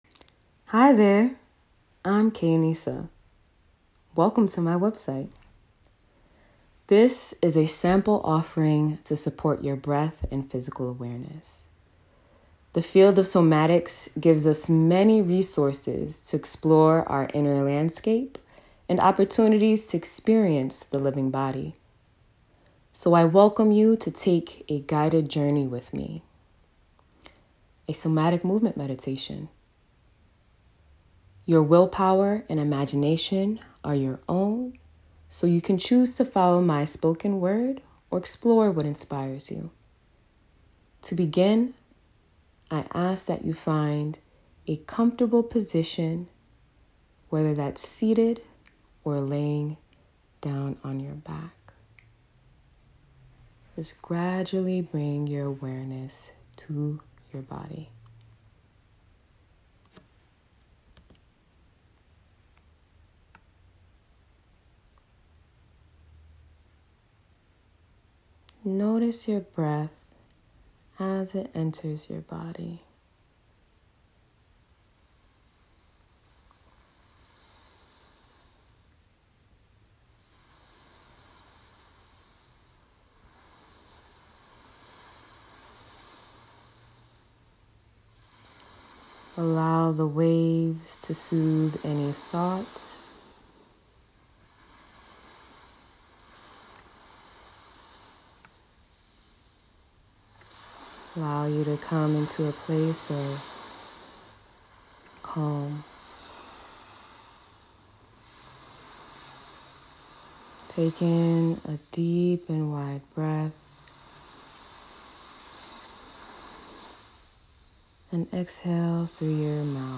Here’s an offering for you! — a somatic meditation